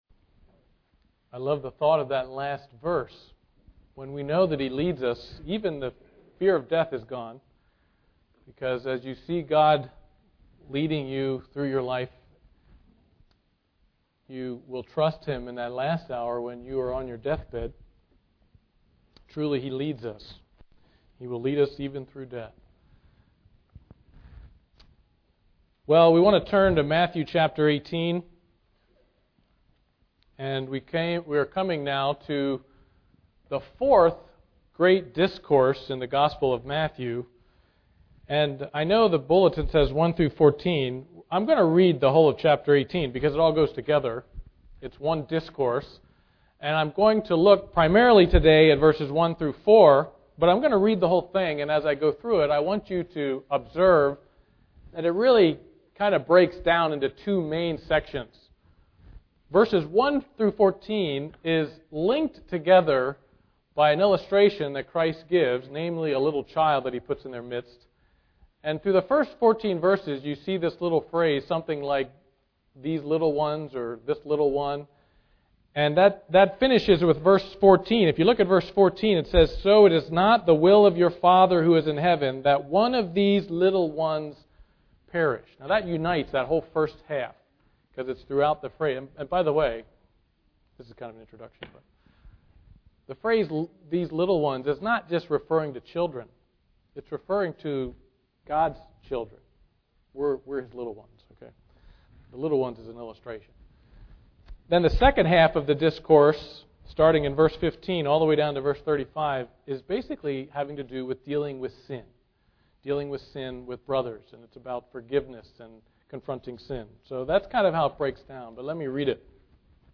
Passage: Matthew 18:1-4 Service Type: Sunday Morning Worship